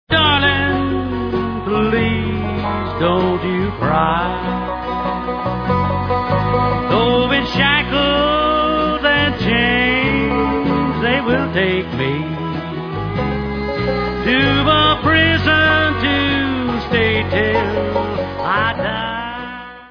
sledovat novinky v oddělení Rock/Bluegrass